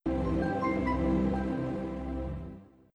Samsung Galaxy S30 Startup.wav